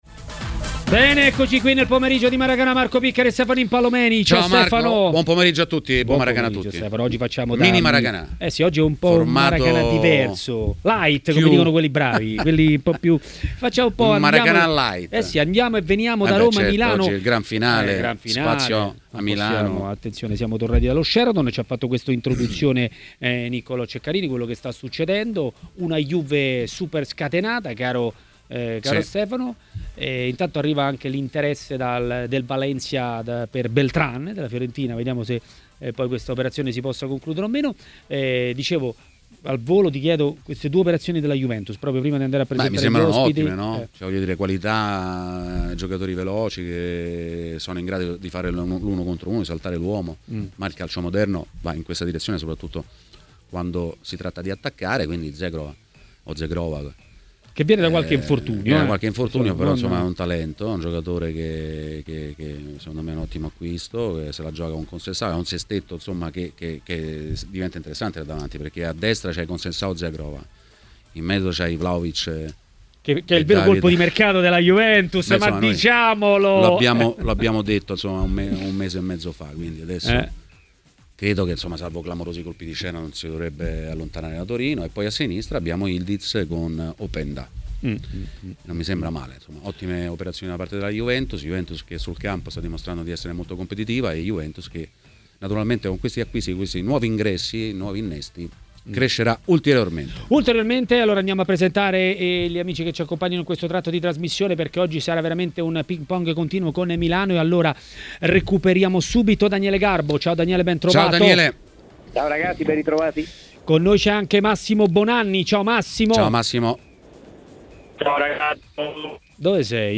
Ospite di Maracanà, trasmissione di TMW Radio, è stato l'ex calciatore Roberto Tricella.